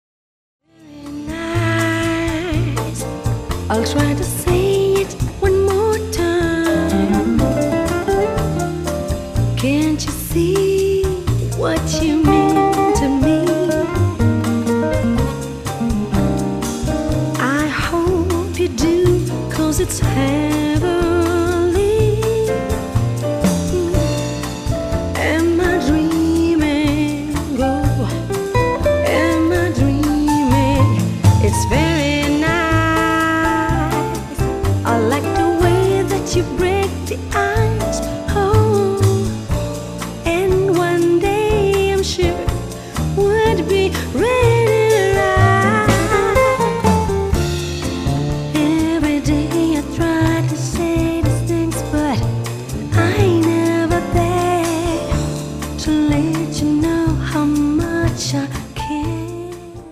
piano, keyboards
acoustic guitar
drums
lead vocals